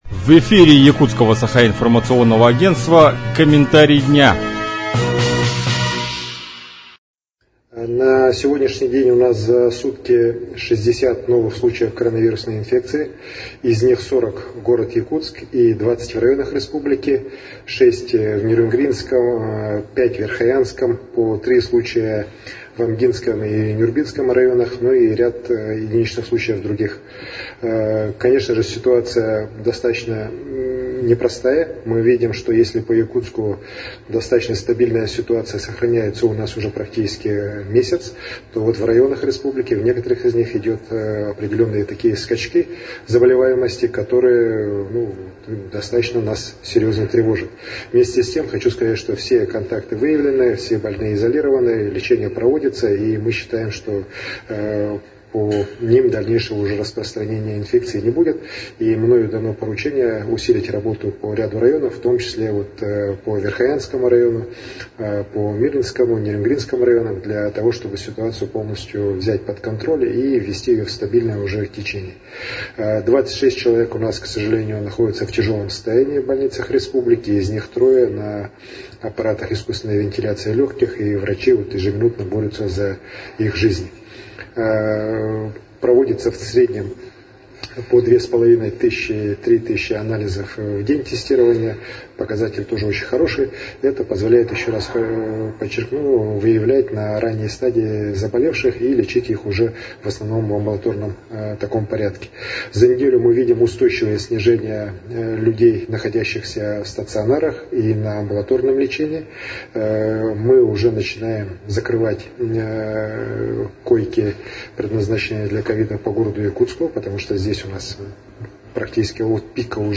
Какова обстановка в Якутии на 31 июля, рассказал глава региона Айсен Николаев.